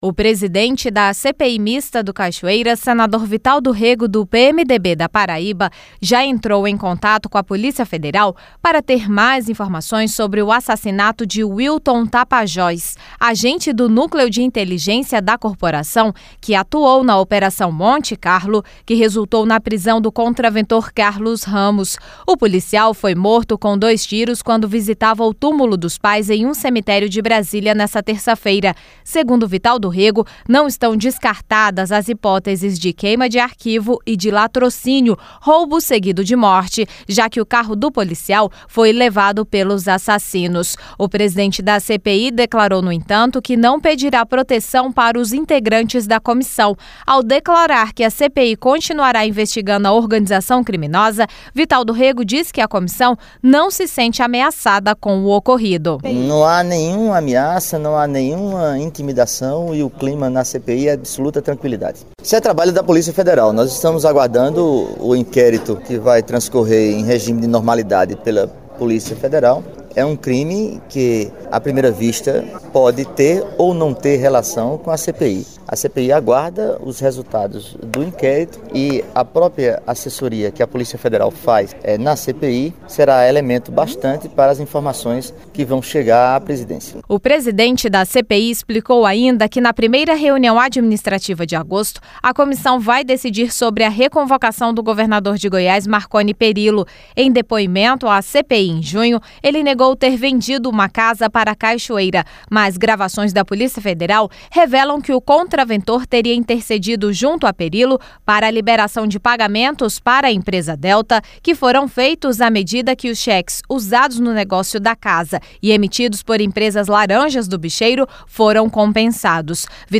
Senador Vital do Rêgo